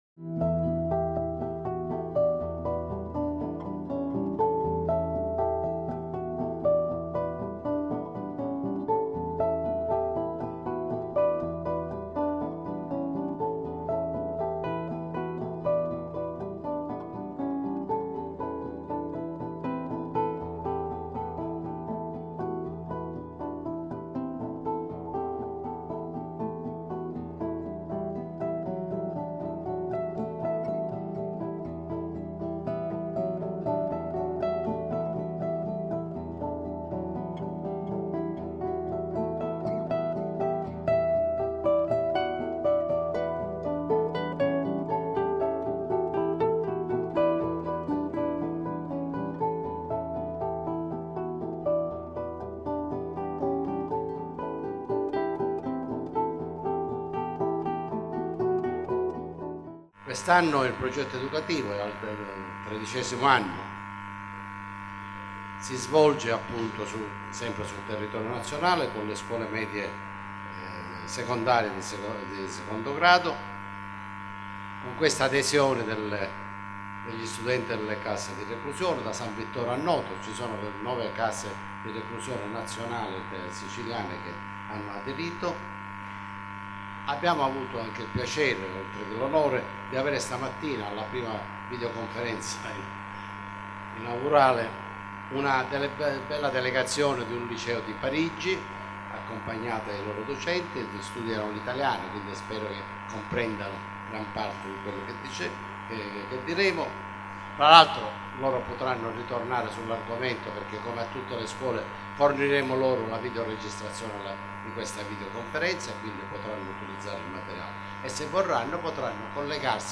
I Conferenza �La storia della mafia e dell�antimafia: evoluzione dal dopoguerra ad oggi�